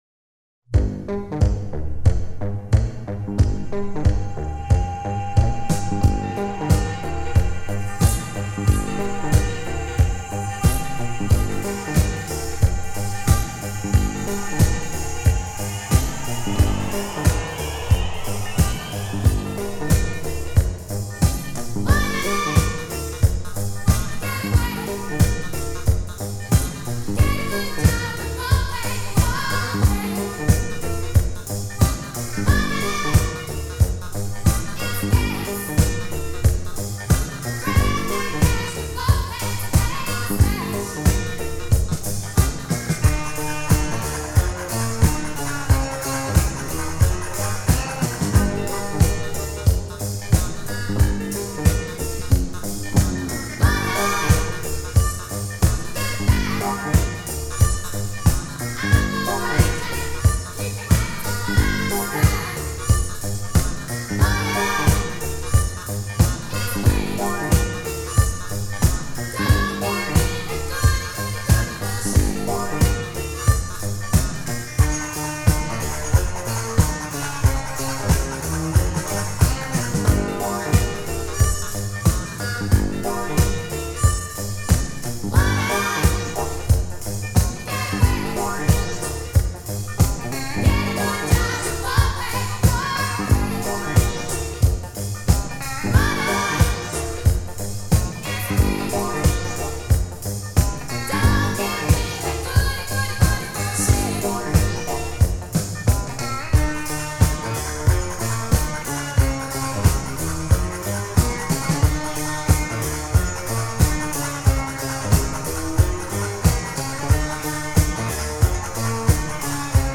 Забавный дисковариант.